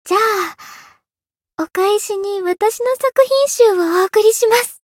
灵魂潮汐-爱莉莎-圣诞节（送礼语音）.ogg